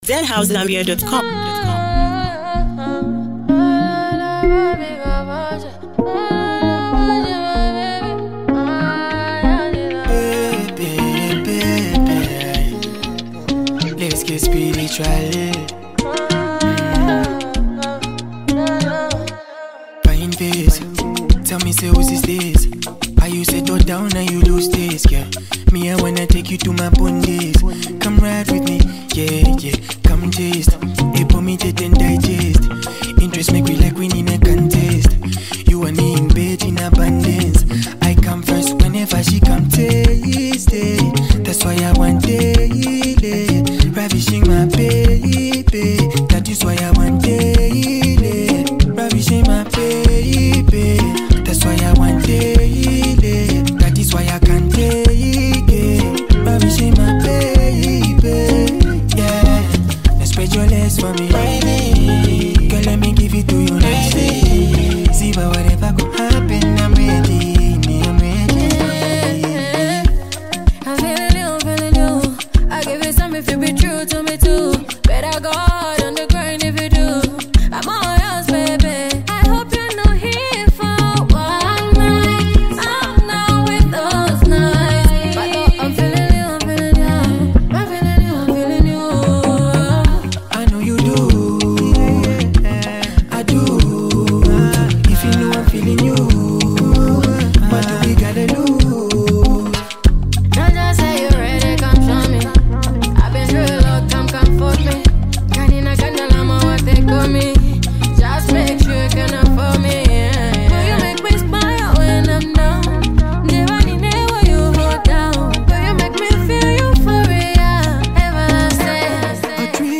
This track is bold, catchy, and empowering